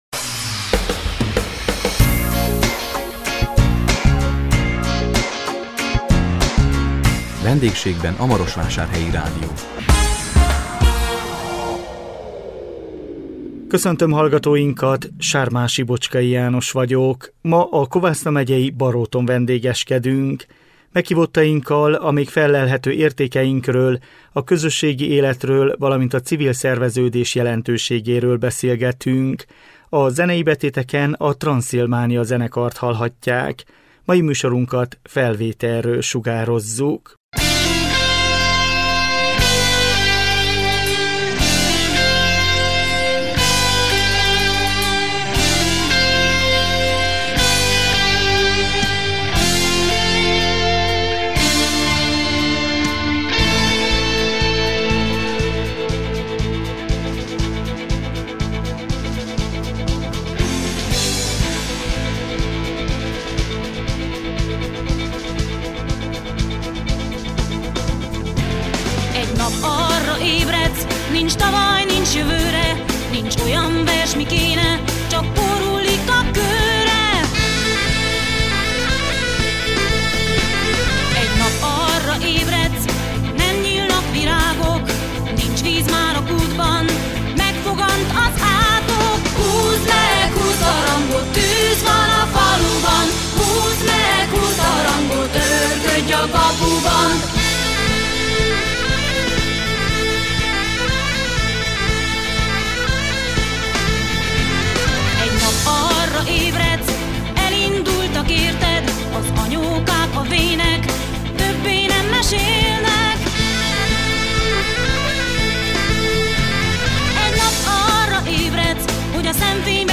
A 2021 szeptember 23-án jelentkező VENDÉGSÉGBEN A MAROSVÁSÁRHELYI RÁDIÓ című műsorunkban a Kovászna megyei Baróton vendégeskedtünk. Meghívottainkkal, a még fellelhető értékeinkről, a közösségi életről valamint a civil szerveződés jelentőségéről beszélgettünk.